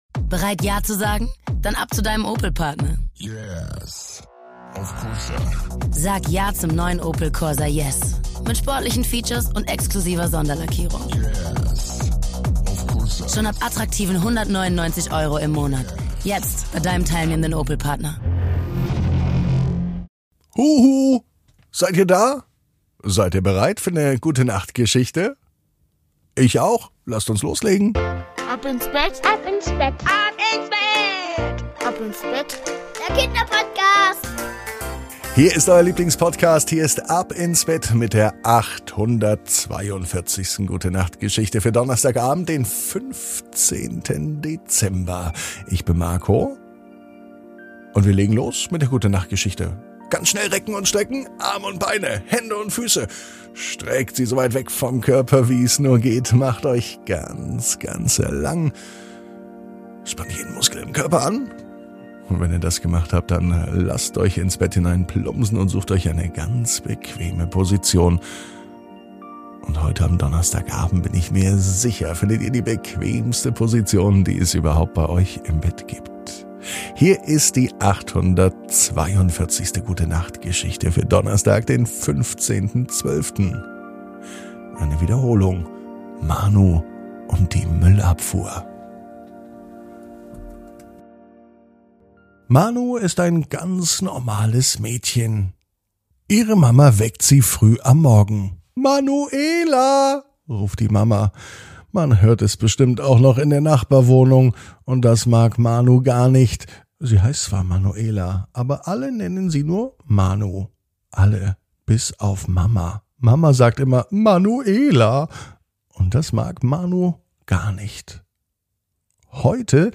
Die Gute Nacht Geschichte für Donnerstag